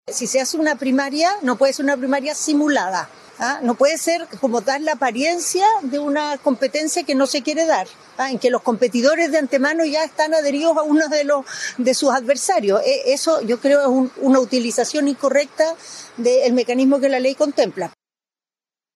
Por su parte, la candidata del PPD, Carolina Tohá, dijo que la primaria que se pretendía levantar en Chile Vamos podía constituir una utilización incorrecta del mecanismo que la ley contempla.